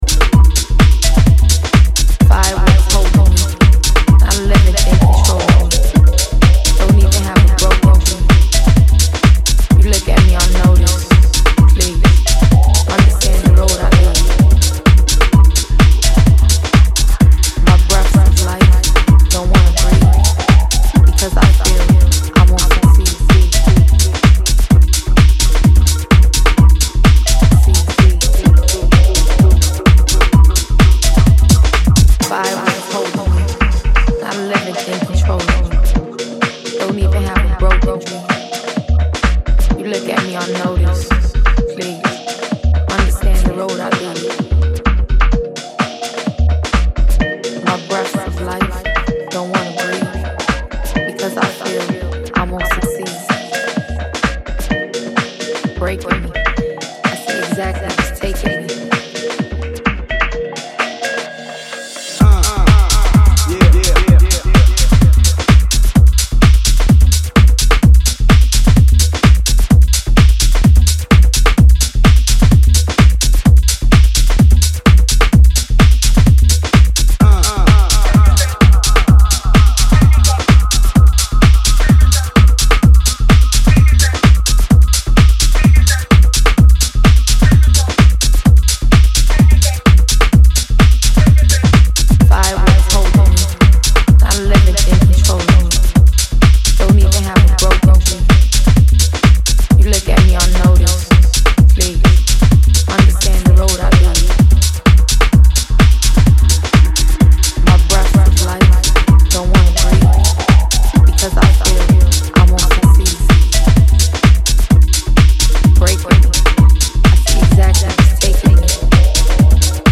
late night and eerie tech
bleepy synths and sultry spoken words